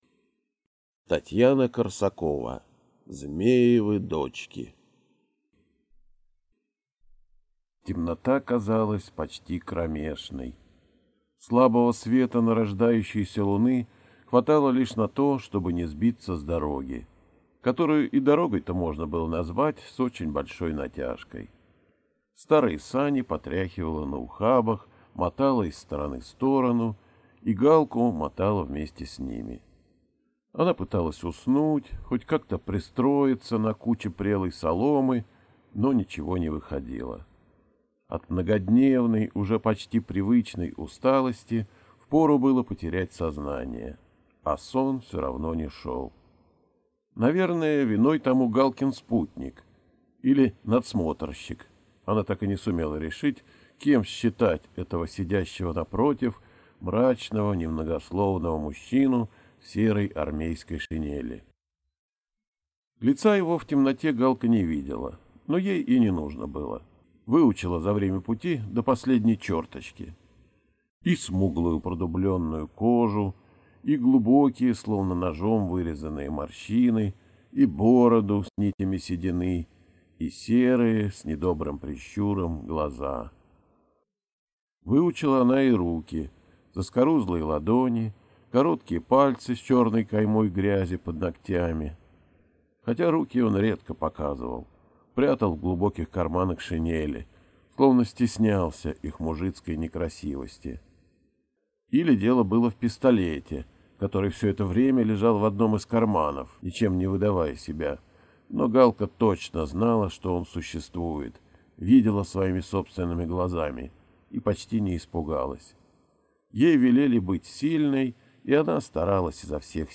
Аудиокнига Змеевы дочки - купить, скачать и слушать онлайн | КнигоПоиск